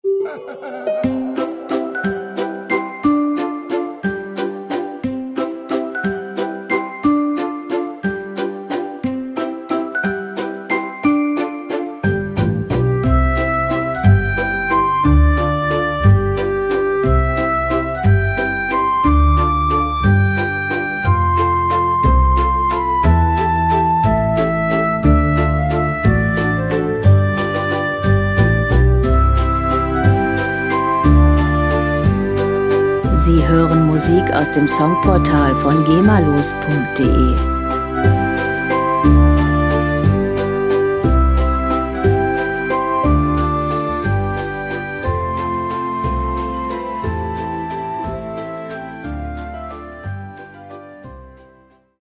• Clownmusik
dieser kleine Zirkuswalzer